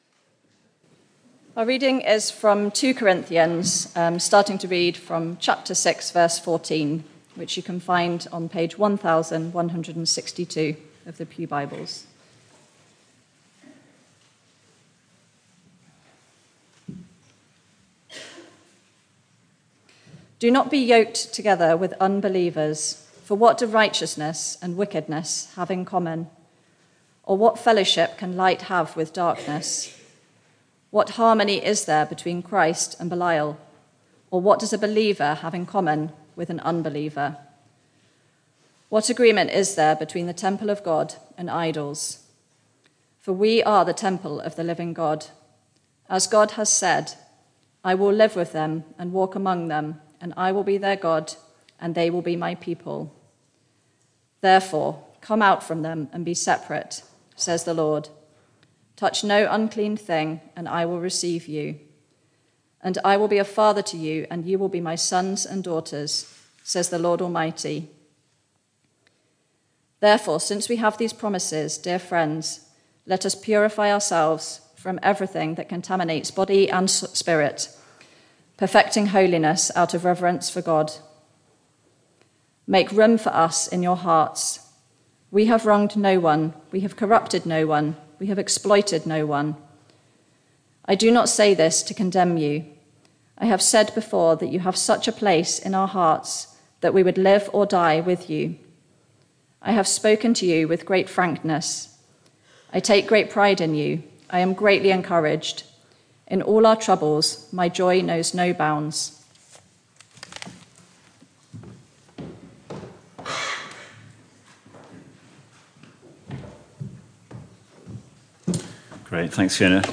Barkham Morning Service
Reading and sermon